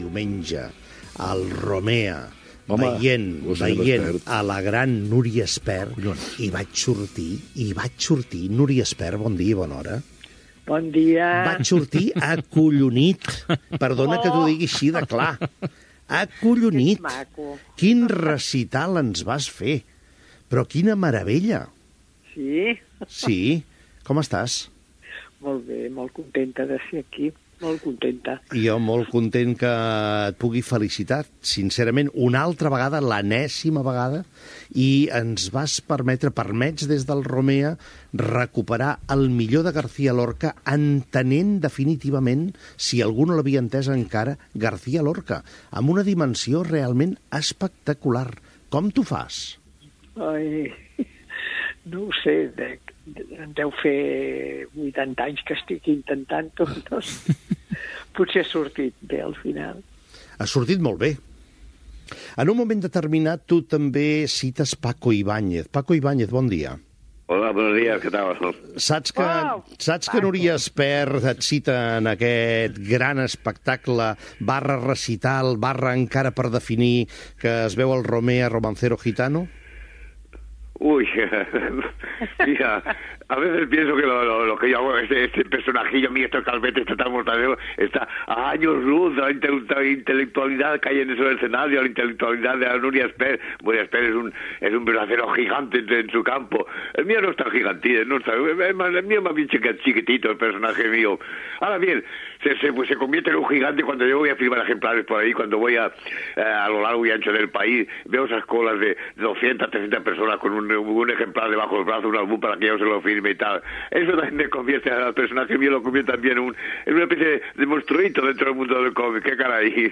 Fragment d'una entrevista a l'actriu Núria Espert i conversa amb el dibuixant Paco Ibáñez (creuen que és el cantautor, però és l'humorista gràfic)
Info-entreteniment